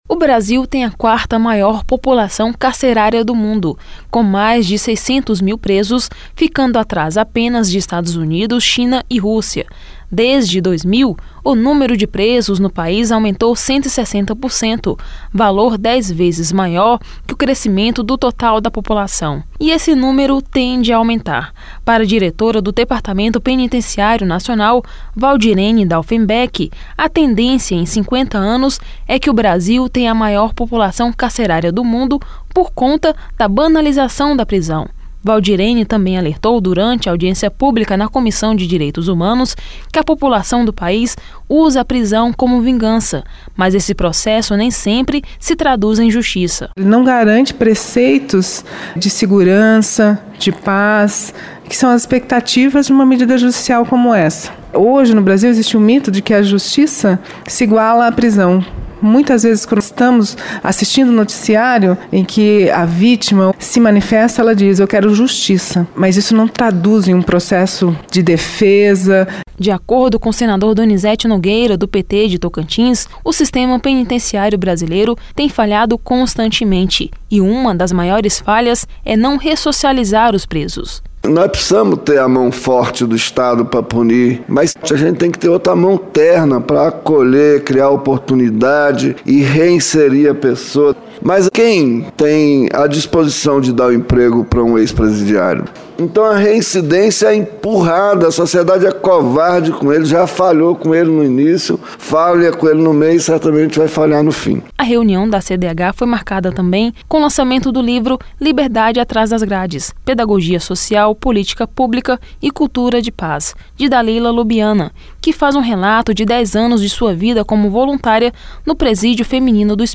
O alerta foi feito nesta quinta-feira (17) pela diretora do Departamento Penitenciário Nacional (Depen), Valdirene Daufemback, durante audiência pública na Comissão de Direitos Humanos e Legislação Participativa (CDH). Ela contou que o Brasil tem a quarta maior população carcerária do mundo, com mais de 600 mil presos, ficando atrás apenas de Estados Unidos, China e Rússia.